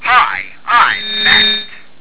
Said loudly